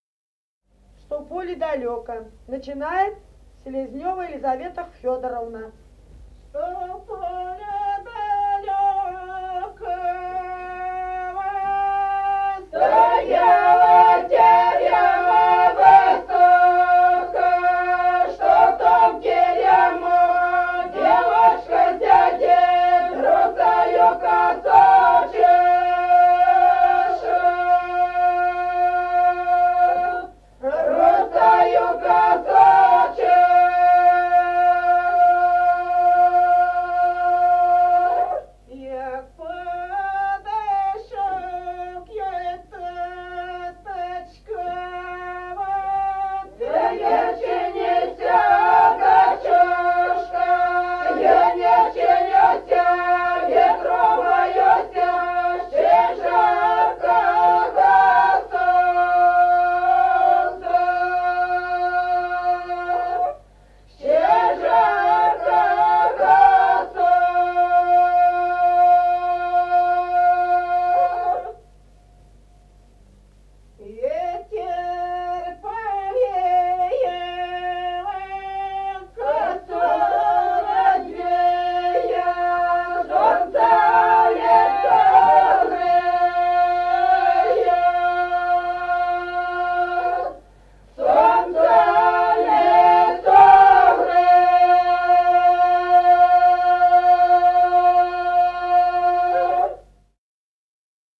Песни села Остроглядово. Что в поле далёко И0070-02